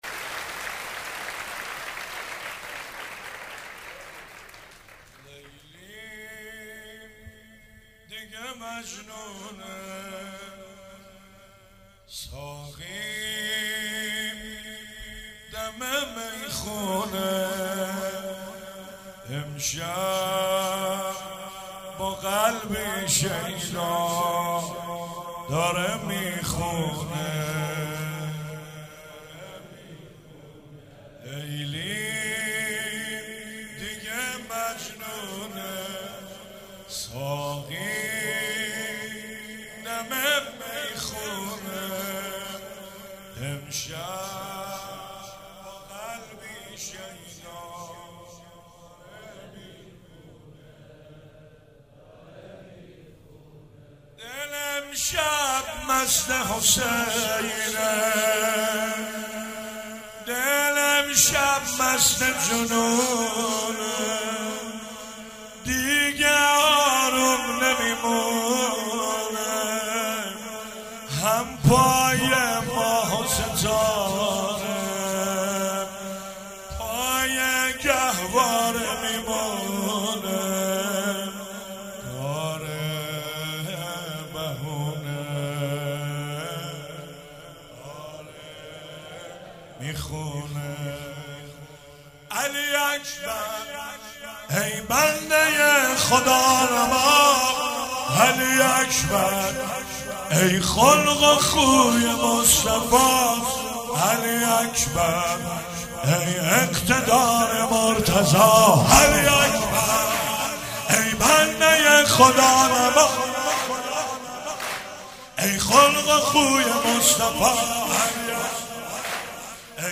چهاراه شهید شیرودی حسینیه حضرت زینب (سلام الله علیها)